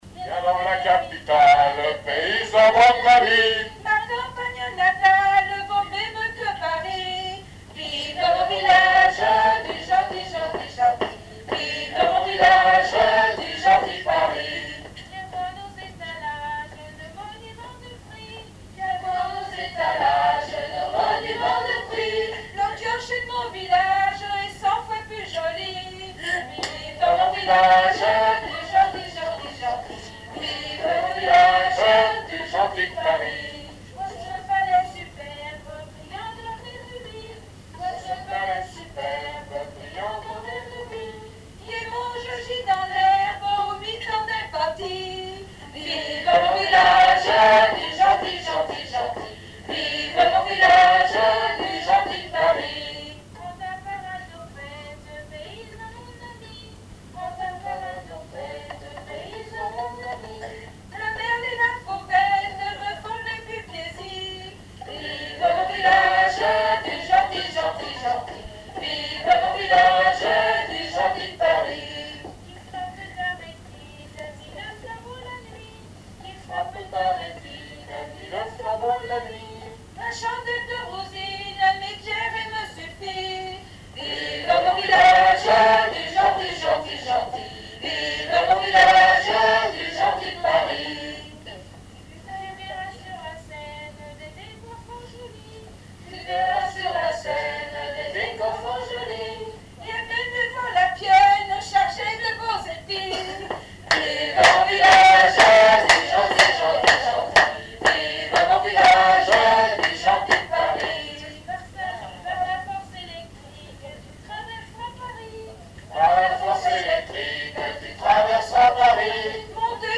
Répertoire d'un bal folk par de jeunes musiciens locaux
Pièce musicale inédite